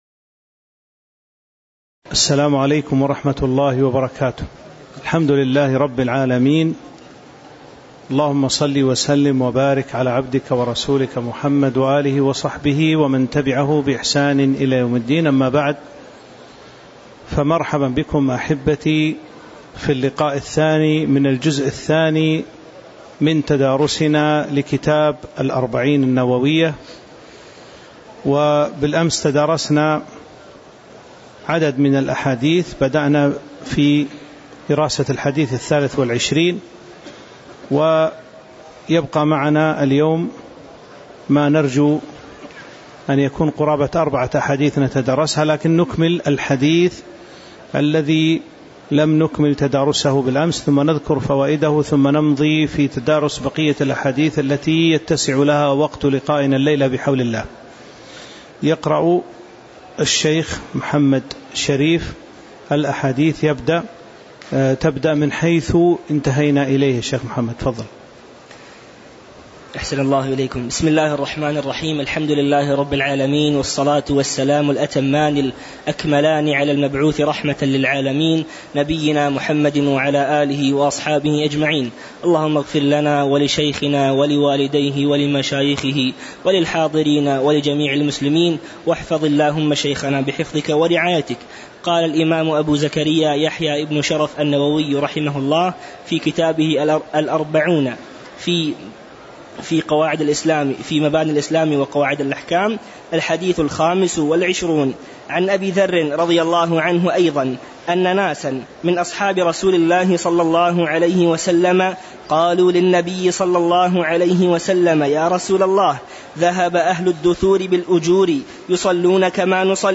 تاريخ النشر ١٩ جمادى الآخرة ١٤٤٥ هـ المكان: المسجد النبوي الشيخ